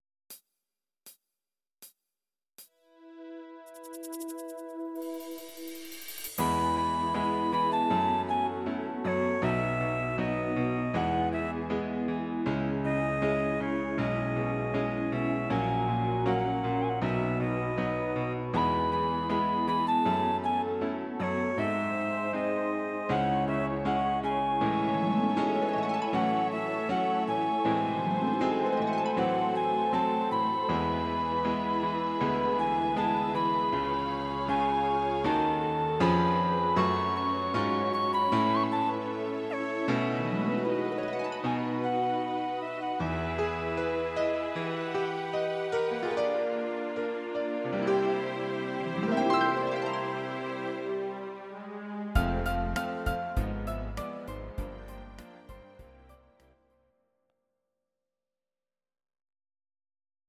Please note: no vocals and no karaoke included.
Your-Mix: Instrumental (2069)